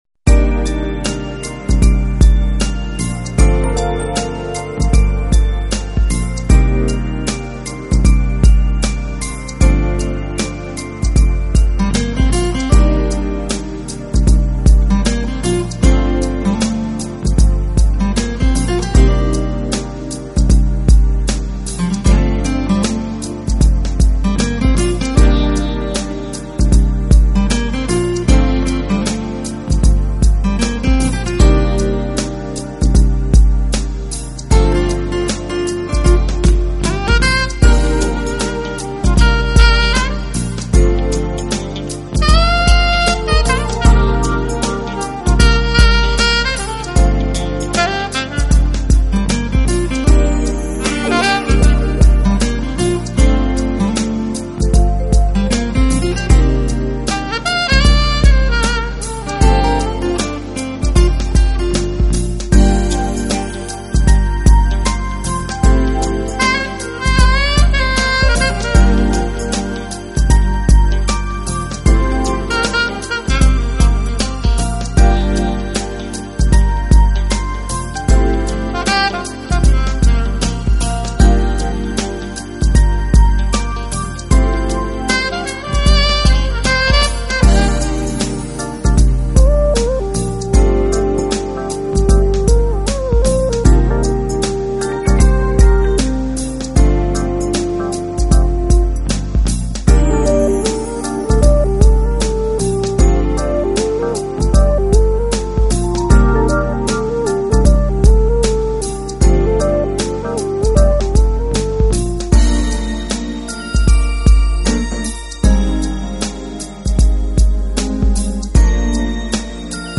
Smooth Jazz是一种非常时尚的音乐类型，当然要领导这个潮流就需要不断的变化，
使他的Smooth Jazz如同和煦清爽的凉风与清凉透心的泉水沁人心脾，令人舒适之极。